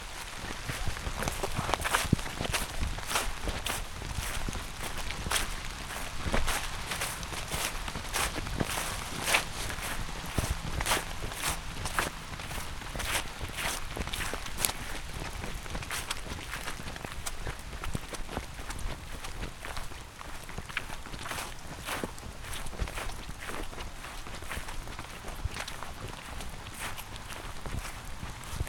Bells Windchime
bell bells chaos ding field-recording lambs rustling stereo sound effect free sound royalty free Nature